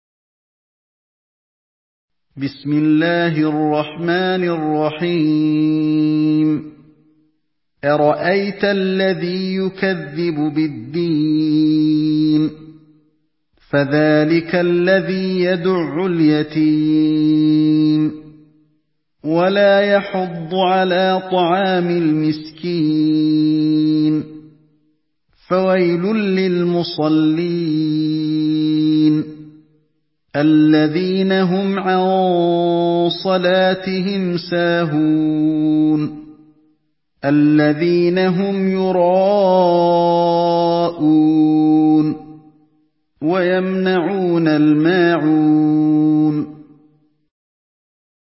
Une récitation touchante et belle des versets coraniques par la narration Hafs An Asim.